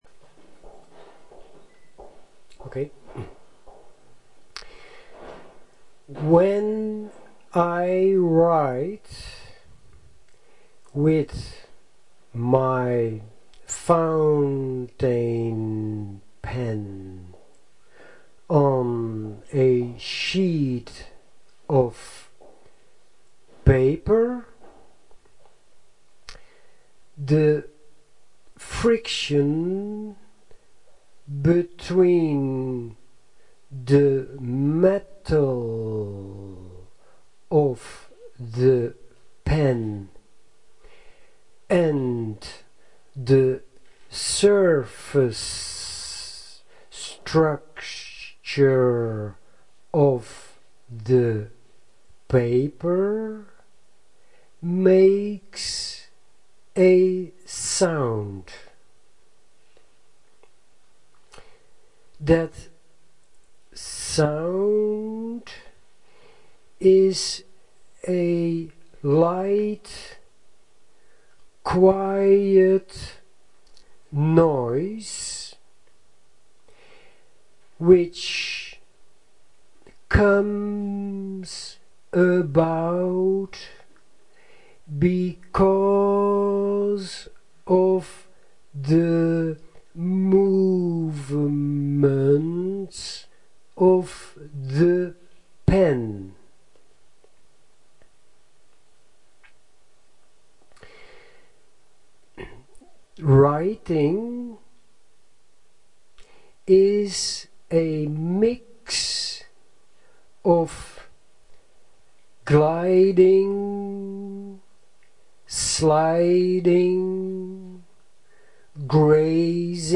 The following is the recording of Sound Scape, carried out live during a SQUID event that took place the 19th of February 2010 in Helsinki, Finland.